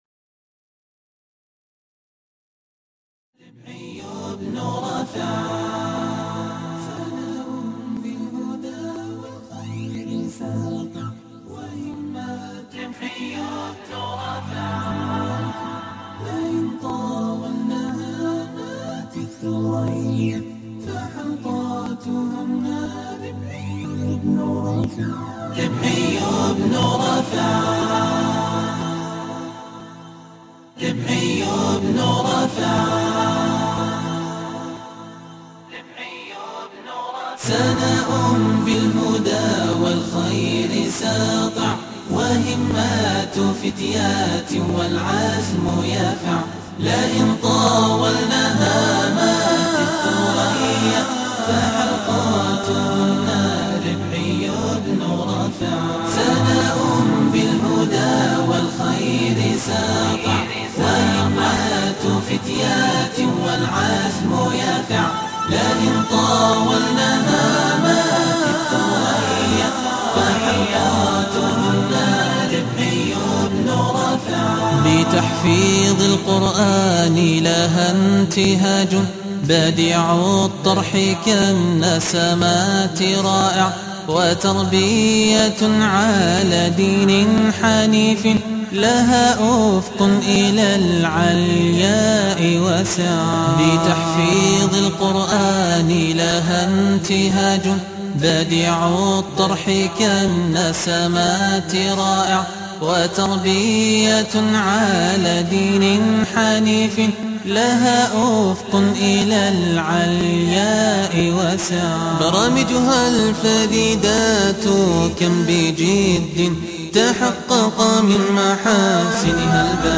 أنشودة خاصة لحفل حلقة ربعي بن رافع